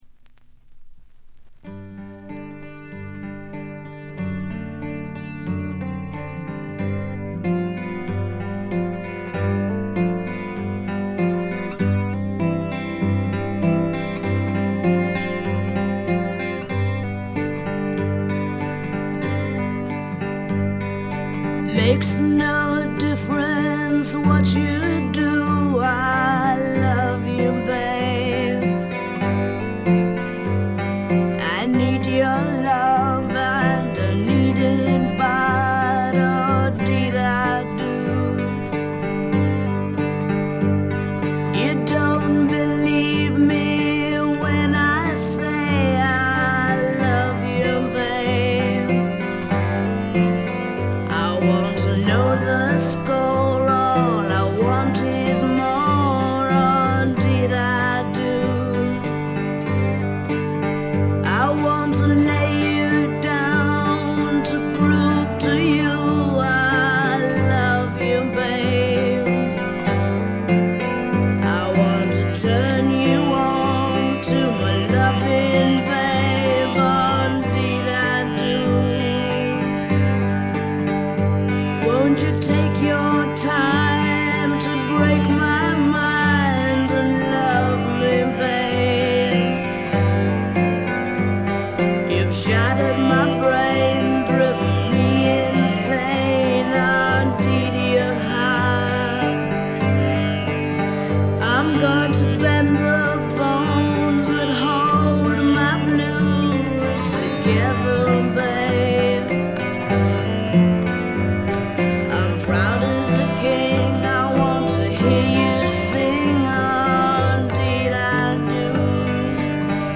カナダ出身の女性シンガーソングライターが残したサイケ／アシッド・フォークの大傑作です。
試聴曲は現品からの取り込み音源です。